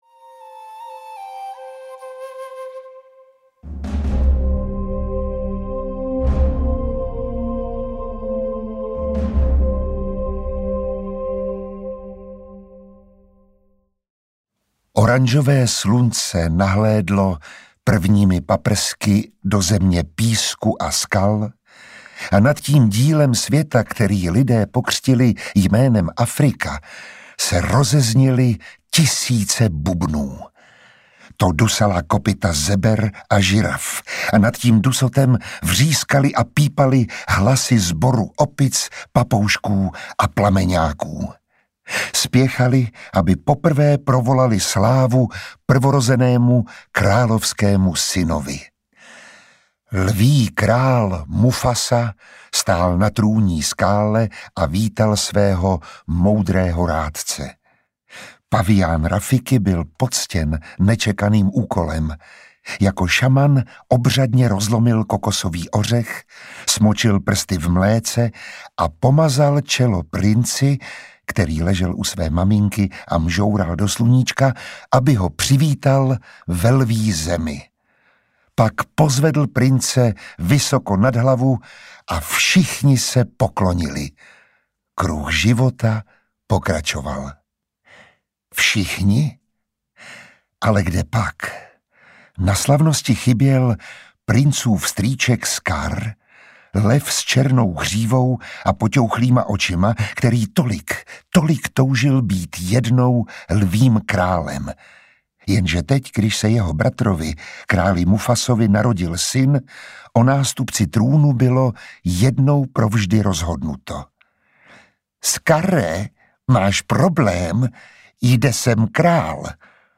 Audio knihaDisney - Lví král, Kniha džunglí, 101 dalmatinů
Ukázka z knihy